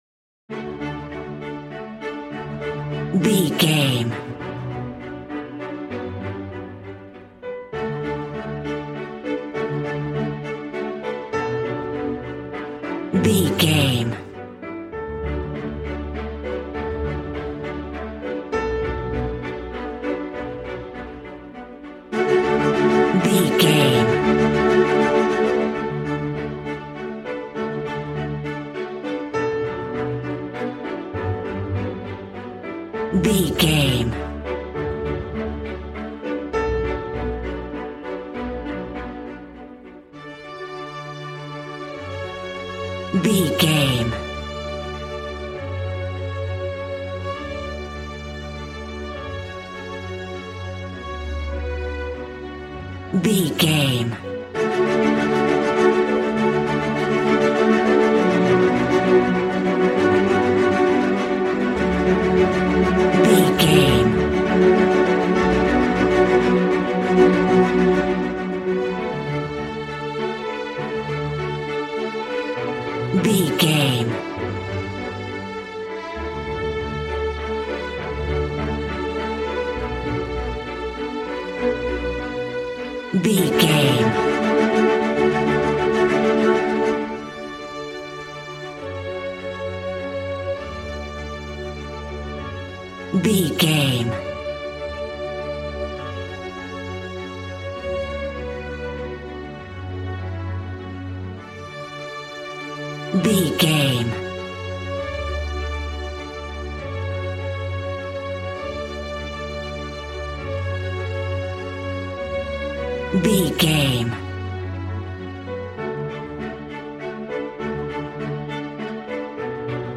Regal and romantic, a classy piece of classical music.
Ionian/Major
regal
cello
violin
strings